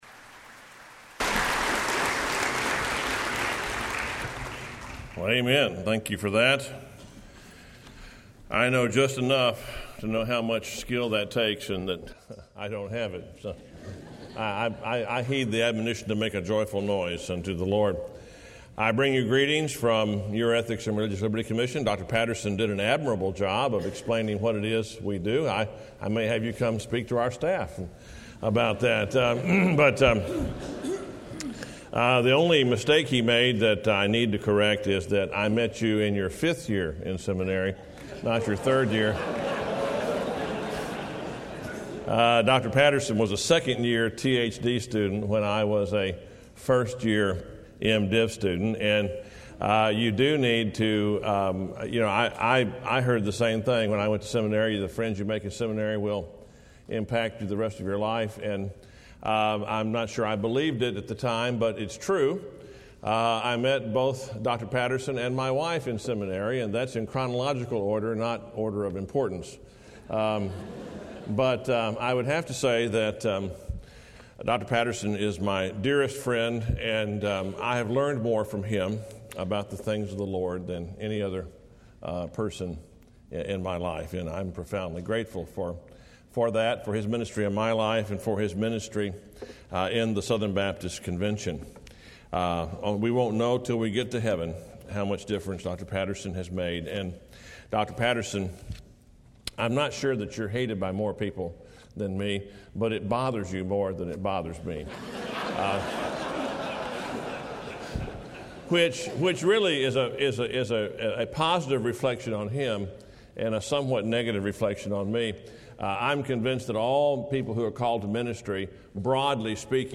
Dr. Richard Land speaking on Matthew 4:1-11 in SWBTS Chapel on Thursday August 27, 2009